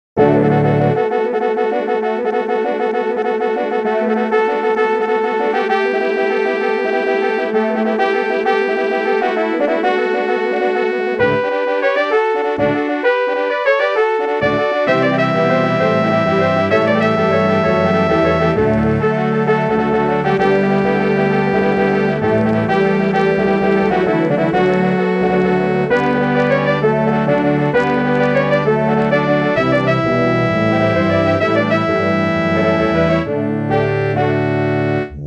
Arrangement / Hornensemble / TV / Film
Bearbeitung für Hornensemble
Besetzung: 8 Hörner, 4 Wagnertuben
Arrangement for horn ensemble
Instrumentation: 8 horns, 4 wagner tubas